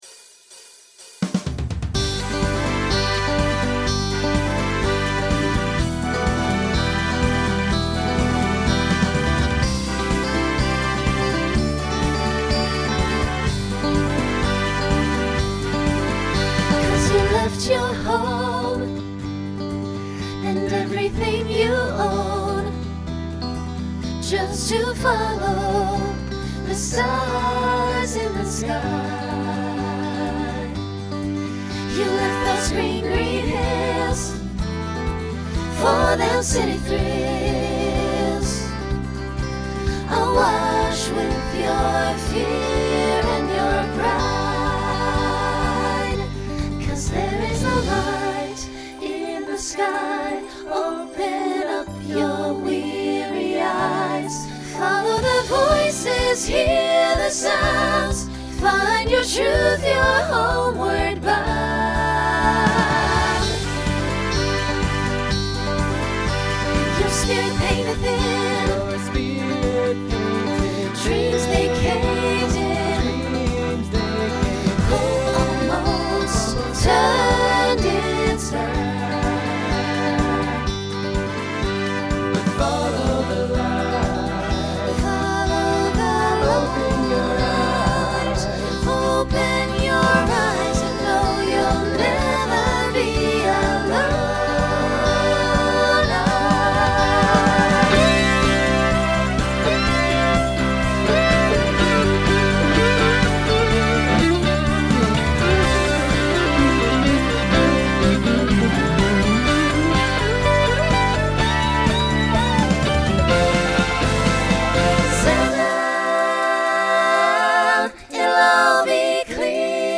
Genre Folk , Rock Instrumental combo
Voicing SATB